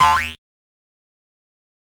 Doink.ogg